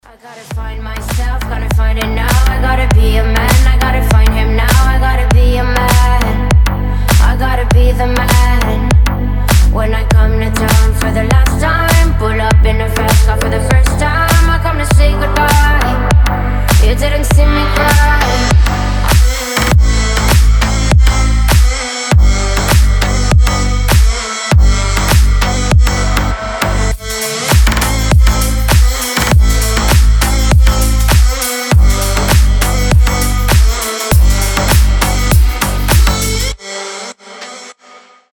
Рингтоны ремиксы
Рингтоны техно
Танцевальные рингтоны